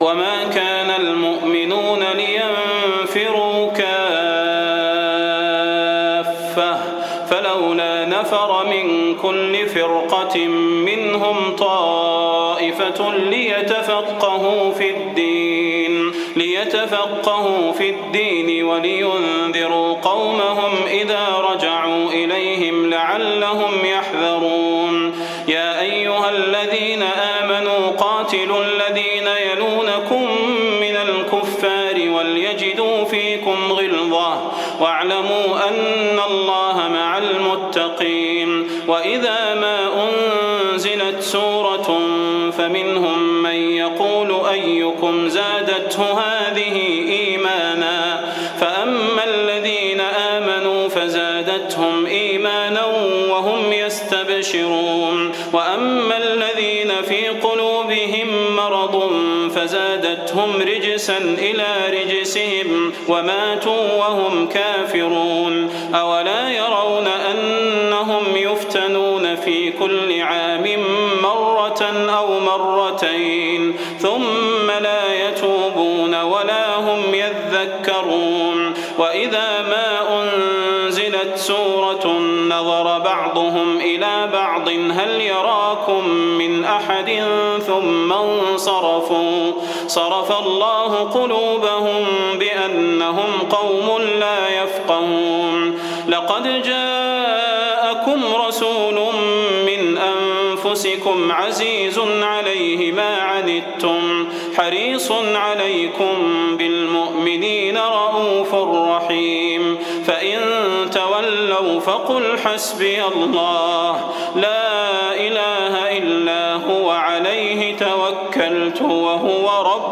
جديد - التوبة 122-129 البدير تهجد 1428